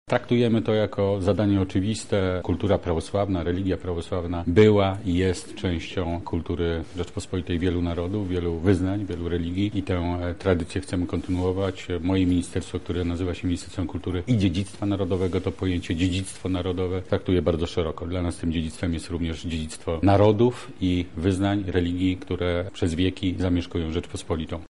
• mówi Jarosław Sellin, sekretarz stanu w Ministerstwie Kultury i Dziedzictwa Narodowego.